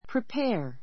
pripéə r プ リ ペ ア